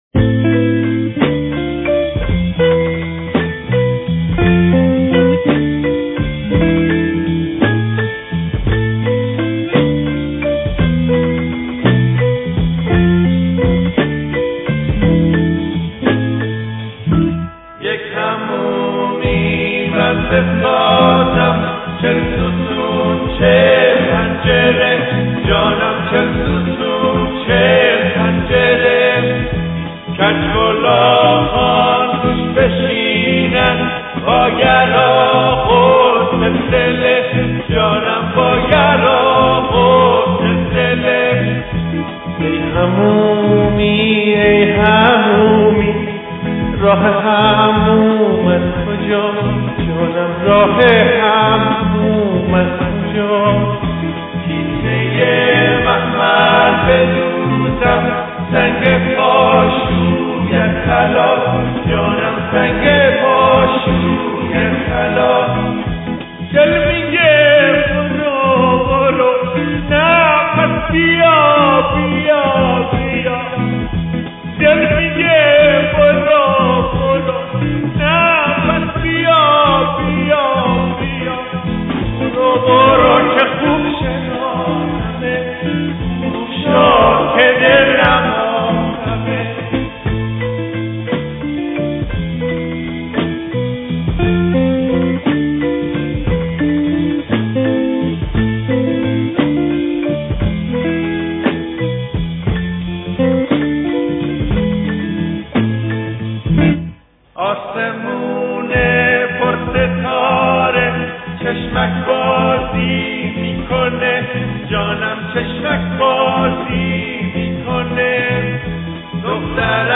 ترانه سرا: (فلکلور)